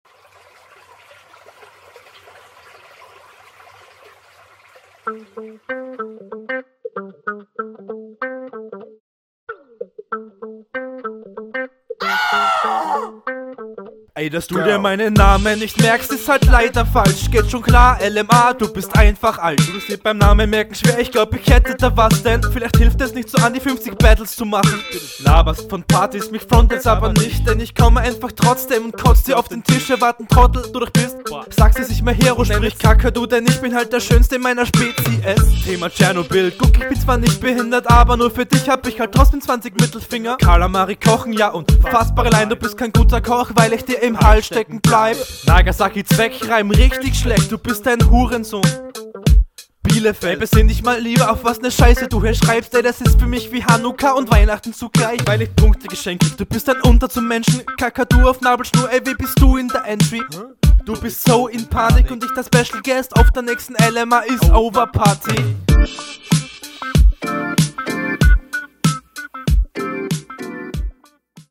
Sound schwächer als dein Gegner.